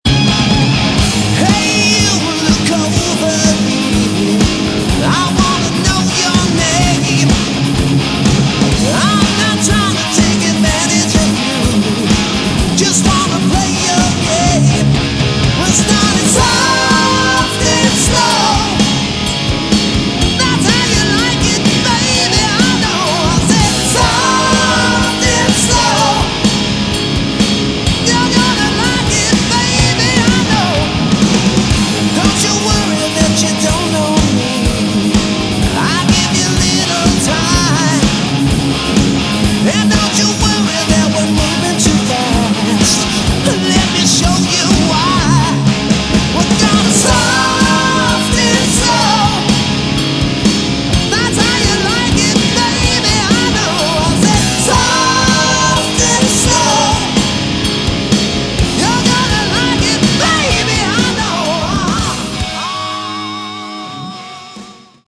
Category: Sleaze Glam / Hard Rock